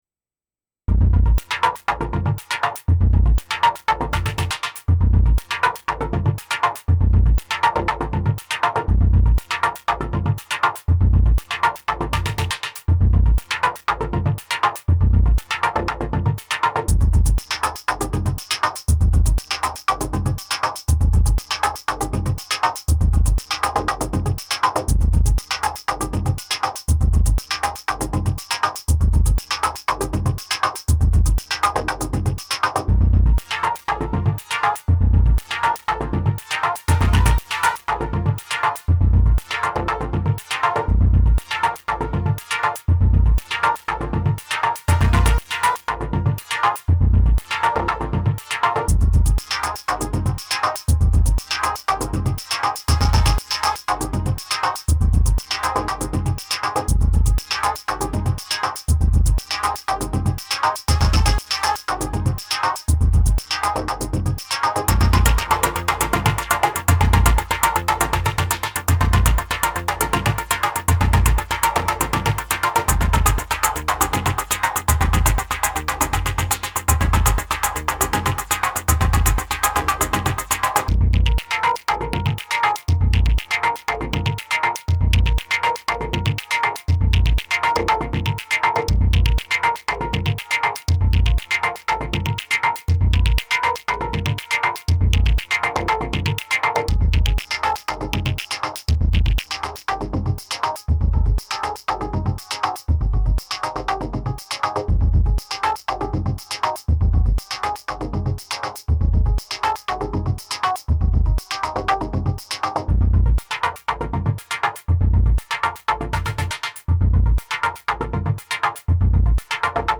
An eclectic collection, all recorded straight from the Syntakt.
A blippy and hyper old school tune.
Exploring a vocoder-like effect (p-locking the FX track filter).